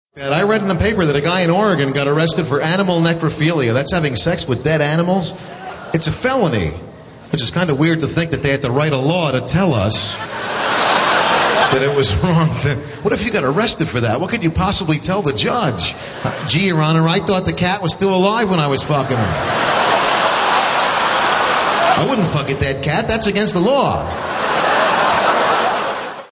Tags: Comedian Robert Schimmel clips Robert Schimmel audio Stand-up comedian Robert Schimmel